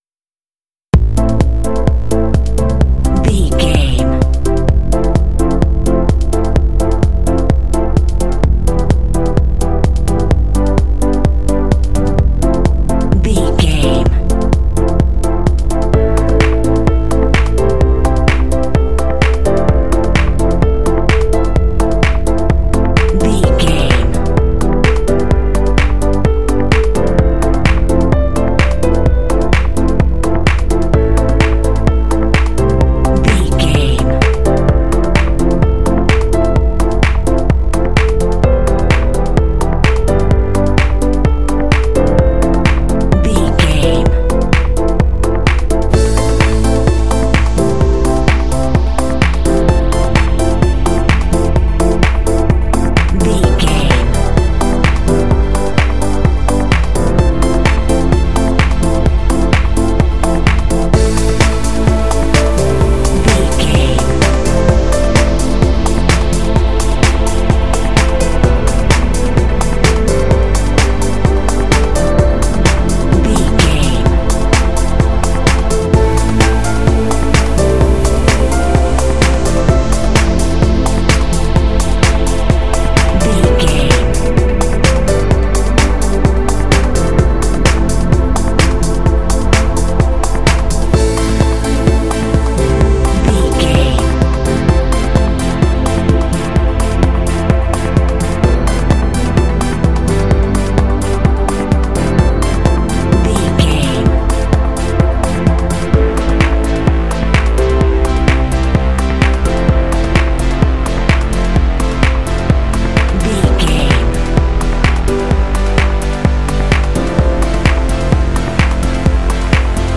Uplifting
Aeolian/Minor
groovy
bouncy
smooth
futuristic
synthesiser
drums
electric piano
techno